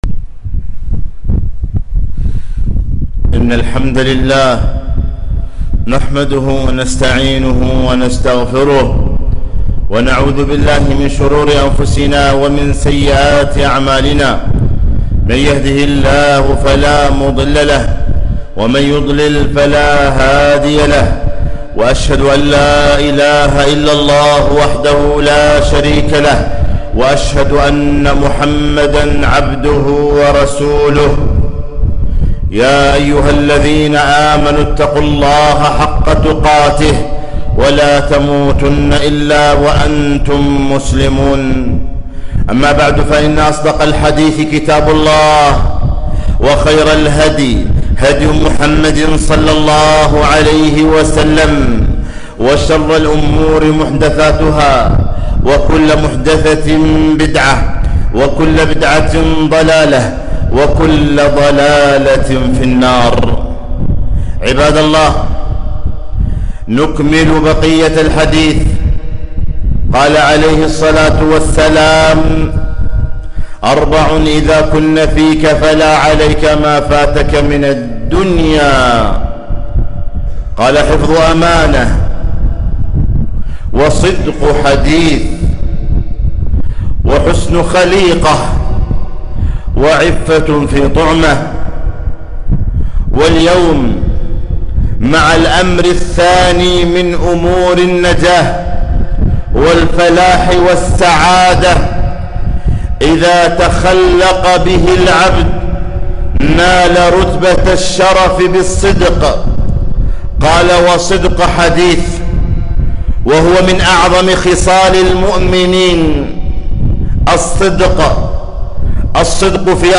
خطبة - الصدق نجاة